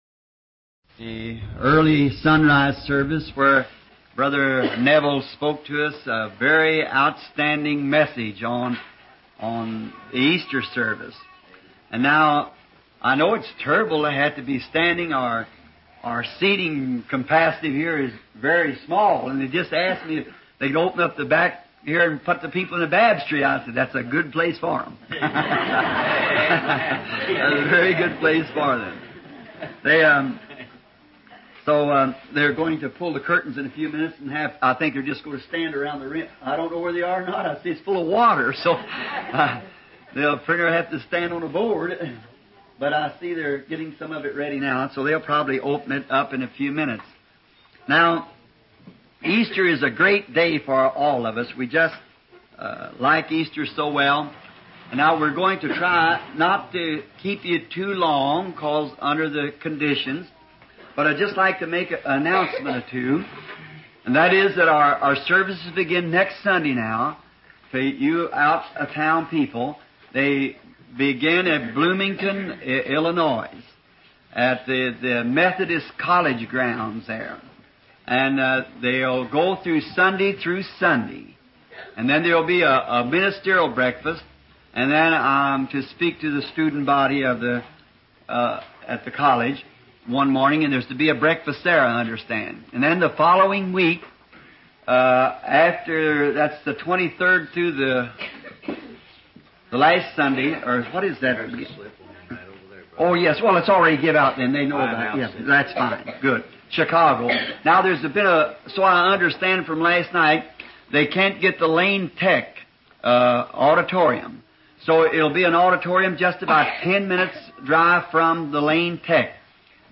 Dieses Portal gibt Ihnen die Möglichkeit, die ca. 1200 aufgezeichneten Predigten